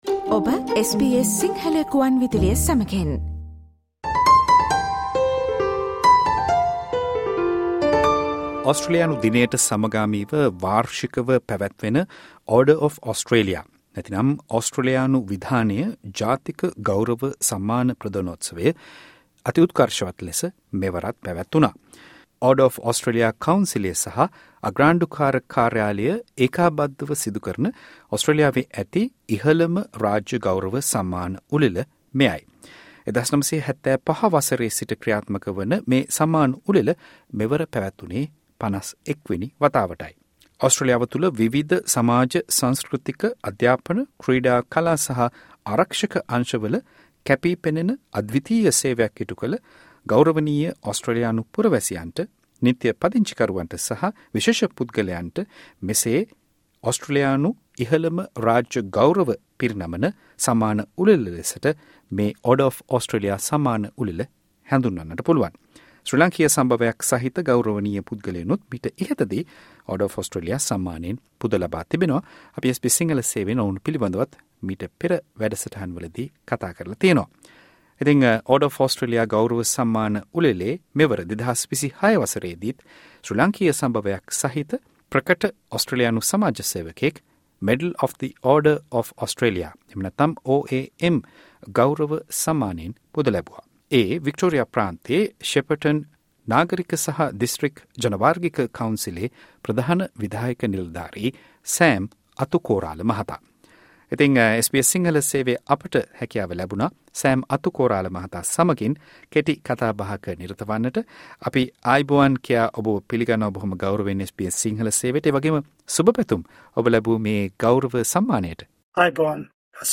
විශේෂ සාකච්ඡාව